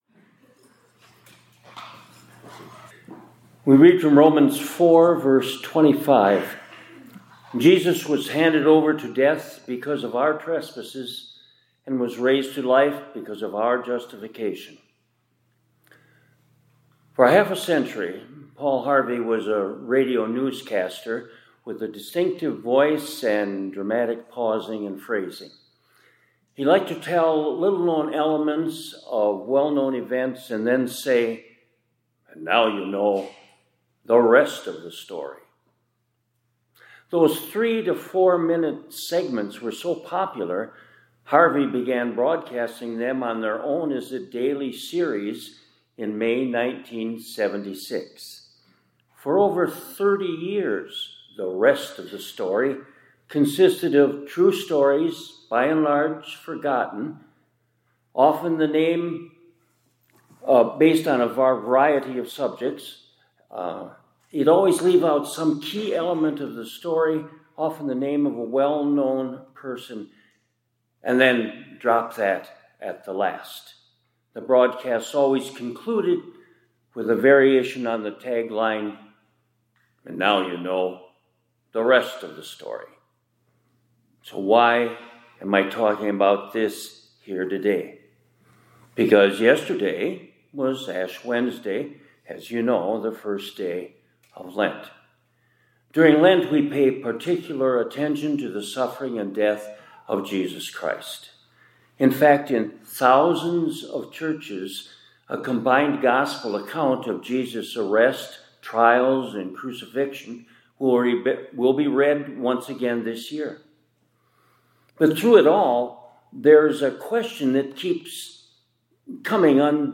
2026-02-19 ILC Chapel — The Rest of the Story